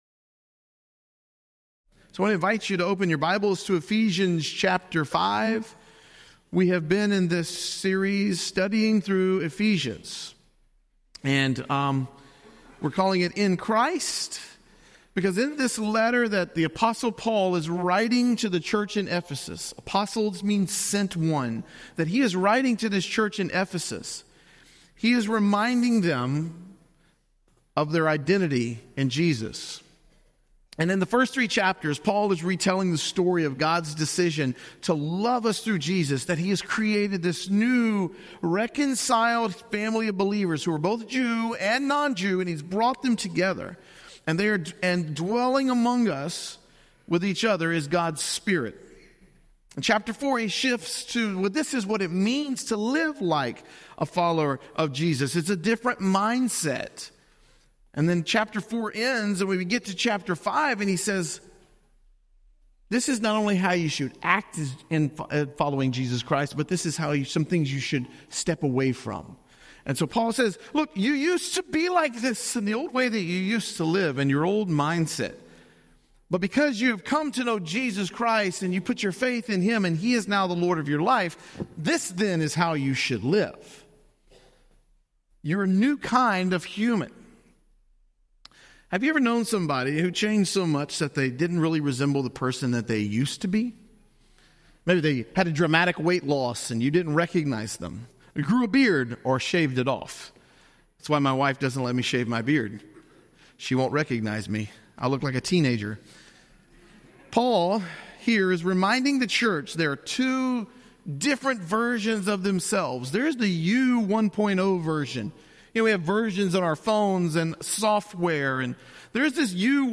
This sermon explores living as children of light, walking in love, and demonstrating the fruit of the Spirit in practical ways. We'll see how our identity in Christ shapes our actions and relationships, guiding us to reflect His light in everyday life.